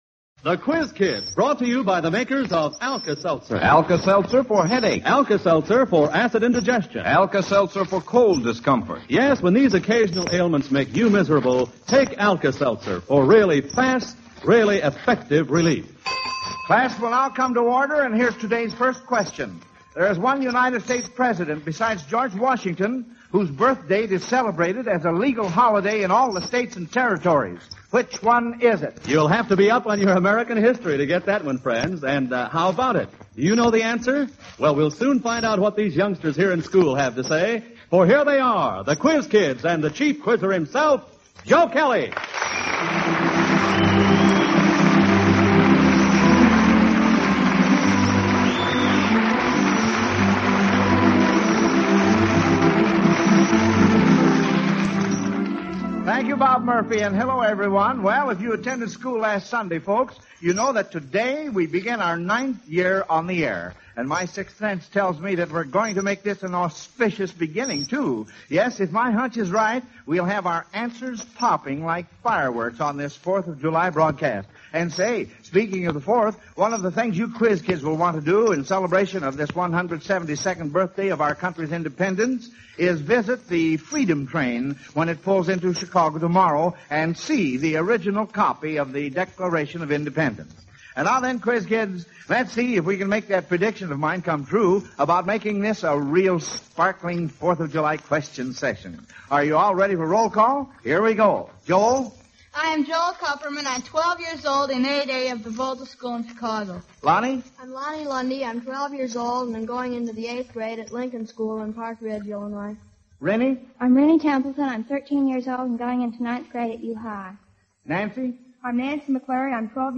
The Quiz Kids Radio Program